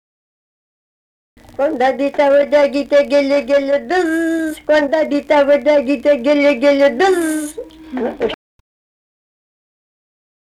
Dalykas, tema daina
Atlikimo pubūdis vokalinis